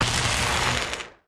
emp-arm-weld.ogg